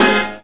JAZZ STAB 15.wav